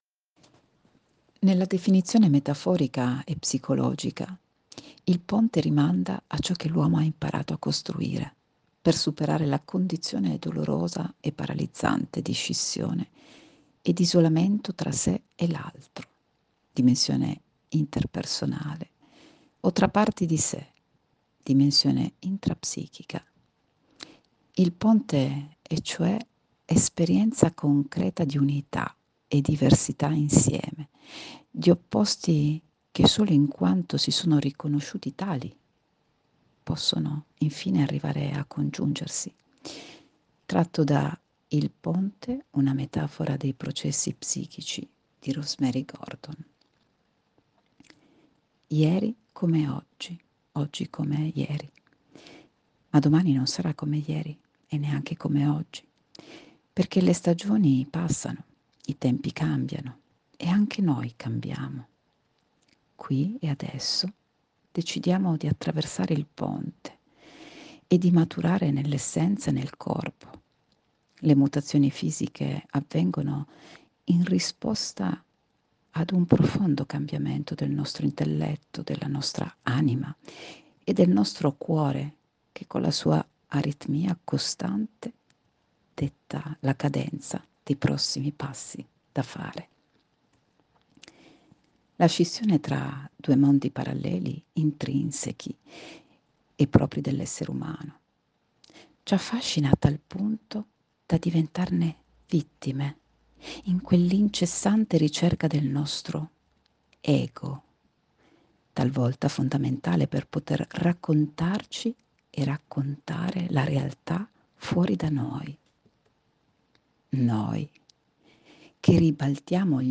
Lettura al link che segue: